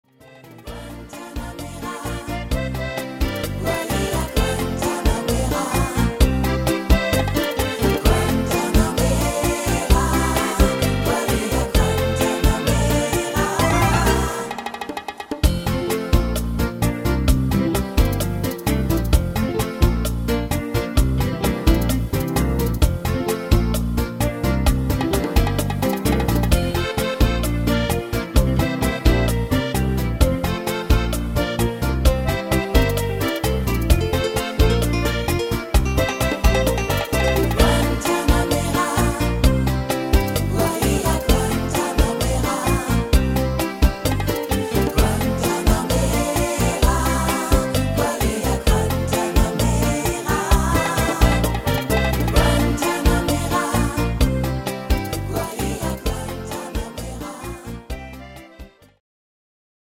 Salsa-Bachata-Version